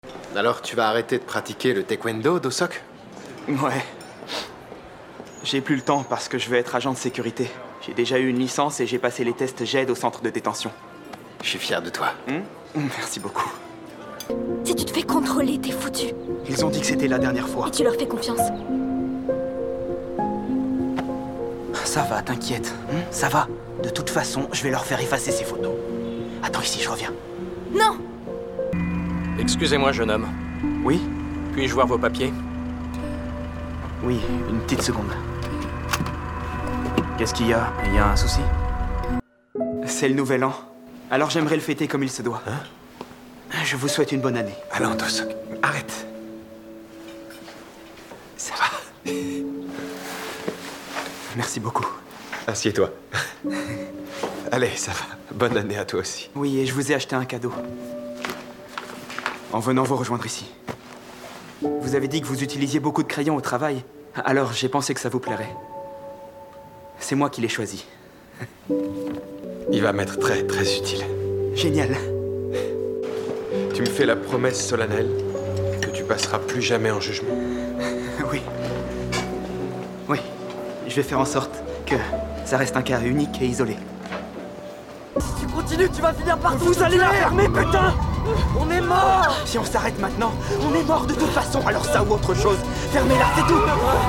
Bande Démo de Doublage
Doublage - Juvenile Justice - Do-Seok
18 - 35 ans - Ténor